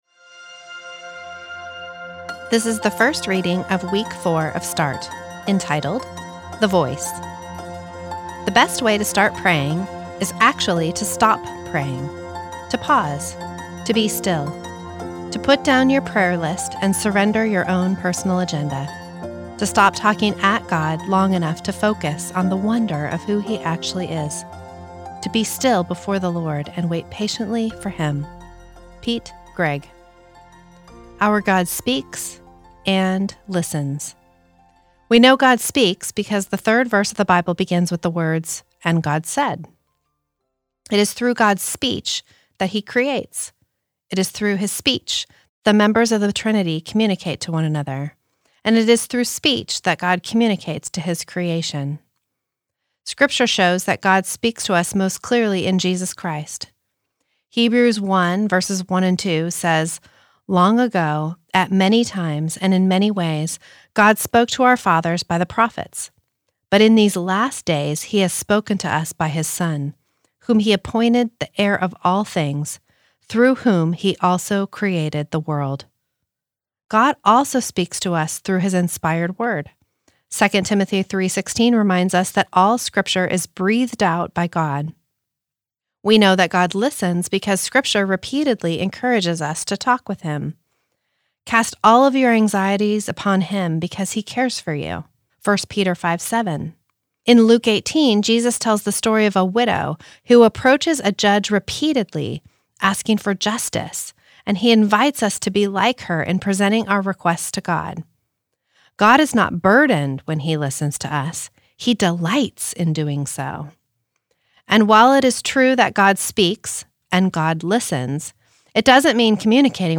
This is the audio recording of the first reading of week four of Start, entitled The Voice.